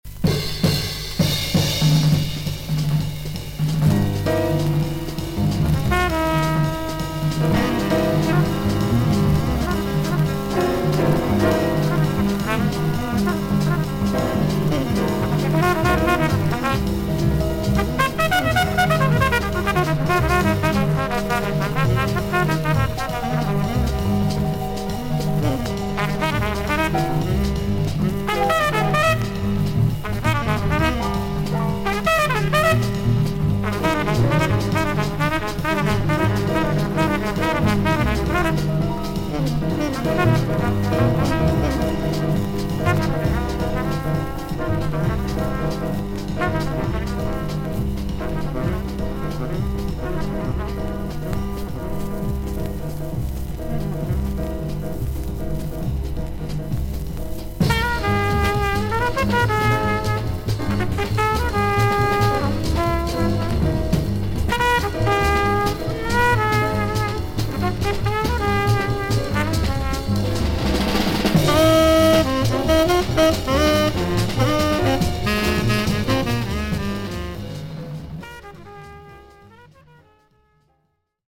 少々軽いパチノイズの箇所あり。少々サーフィス・ノイズあり。クリアな音です。
熱いソロの応酬が楽しめます。